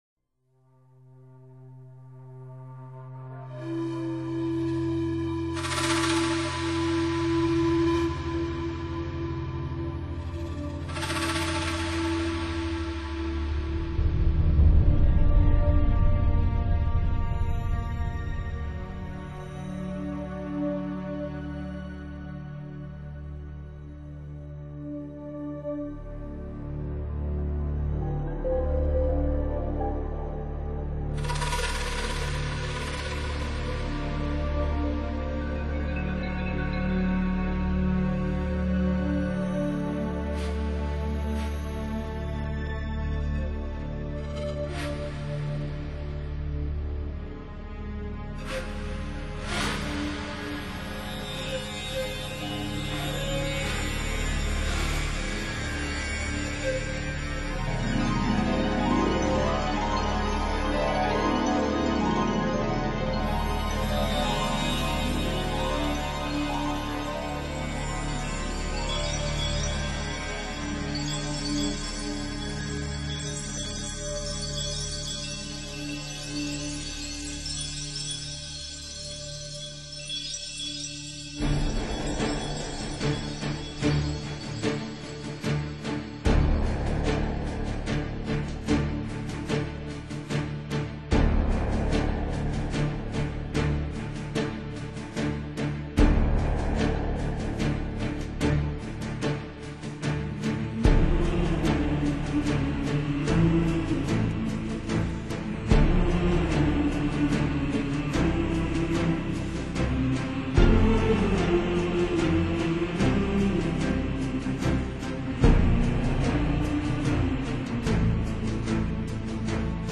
音乐类型：NewAge